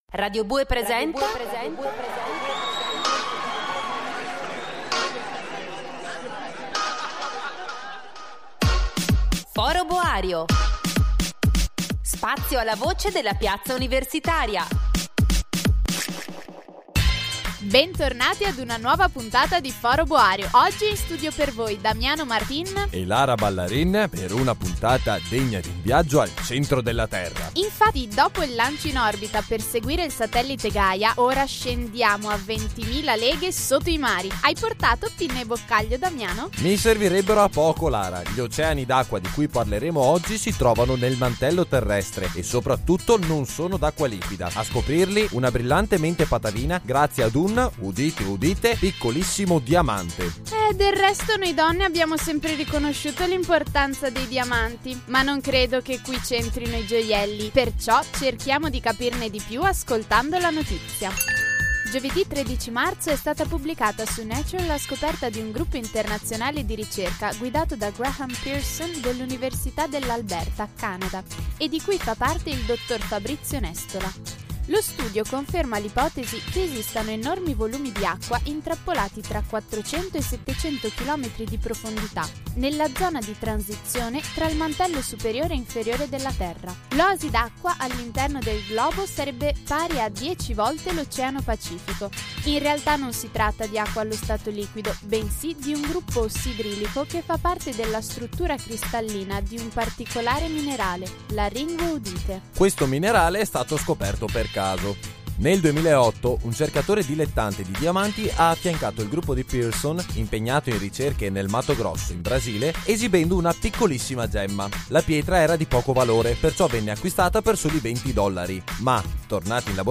Abbiamo intervistato